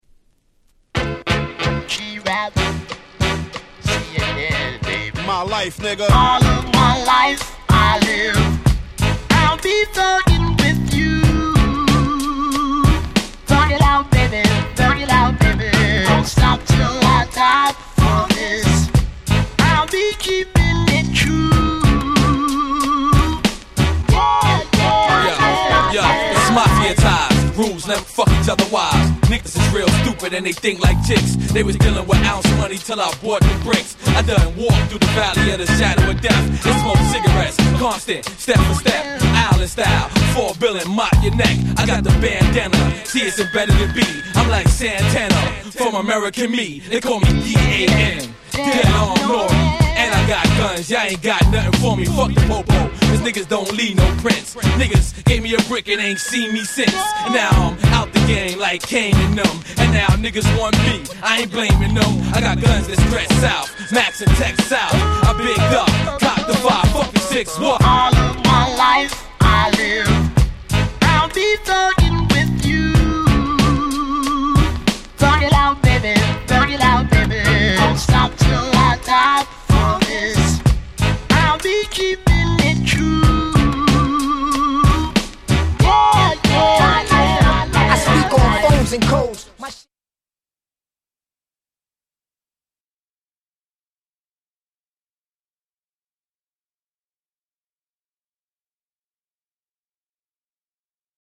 当時はこのキャッチーなネタ使いのおかげもあって異常なまでにヒットしました。